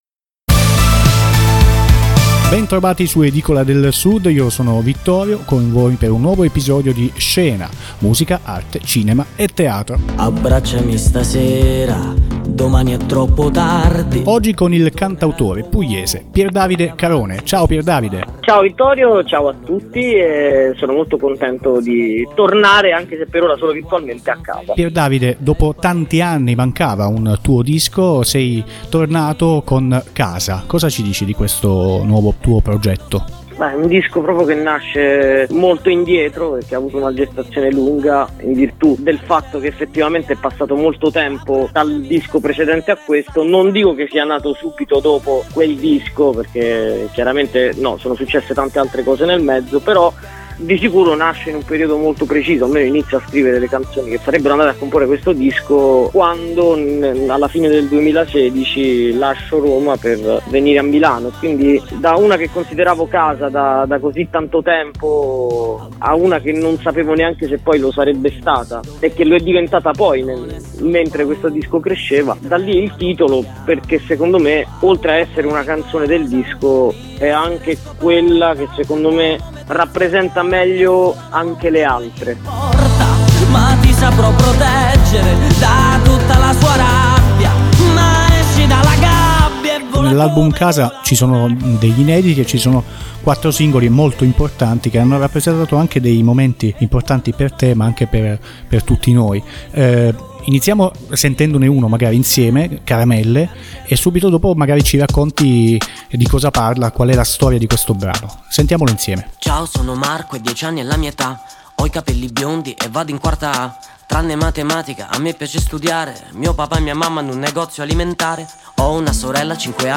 Il cantautore pugliese si racconta in radio e presenta il suo nuovo lavoro discografico, Casa.